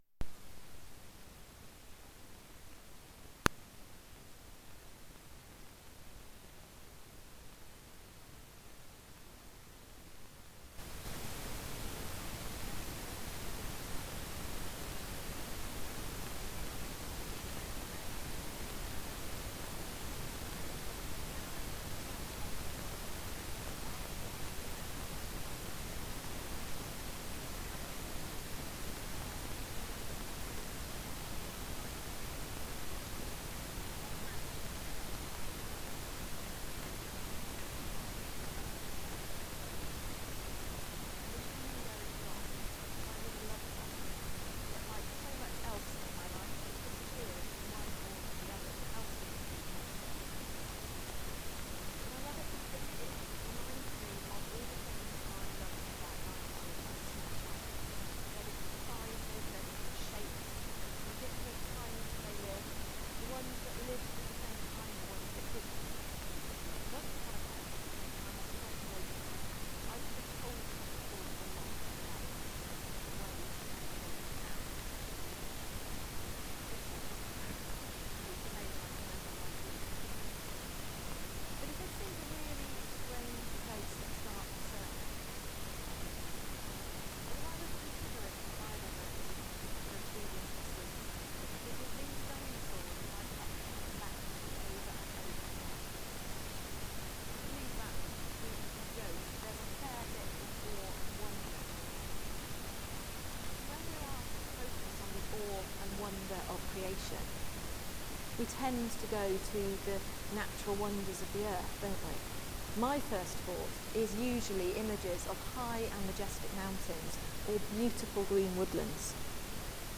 Sermon: Salt | St Paul + St Stephen Gloucester